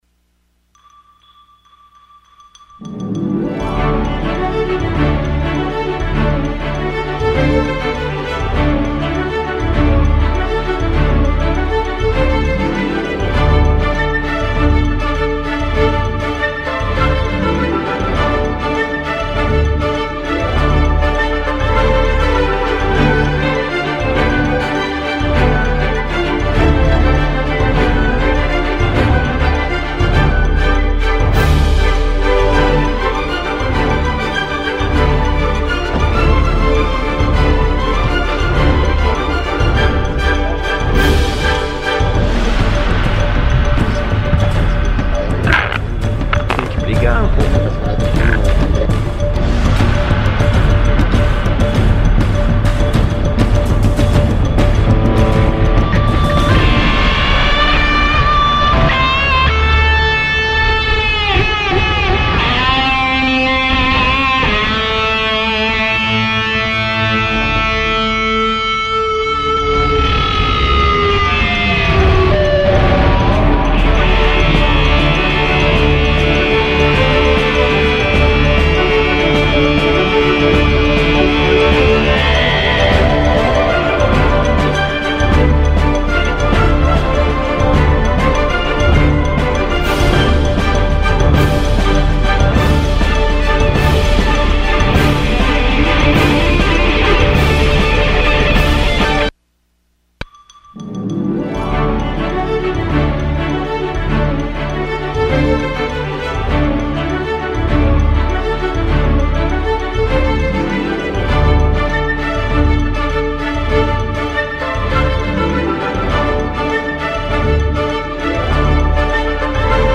Sessão Ordinária dia 04 de julho de 2016.